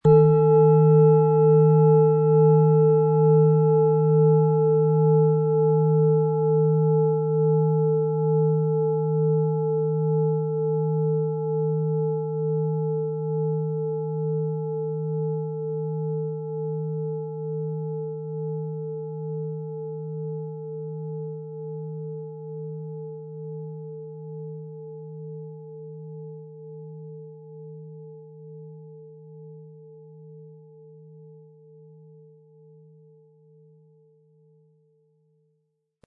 Planetenton
MaterialBronze